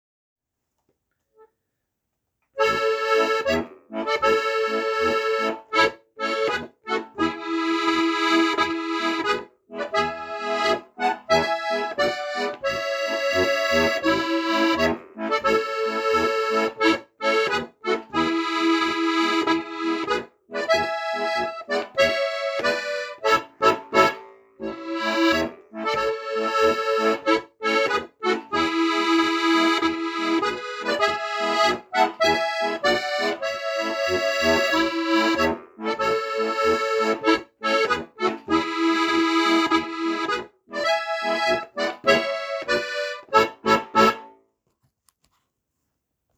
Ratespiel-Thread, Steirische-Harmonika-Edition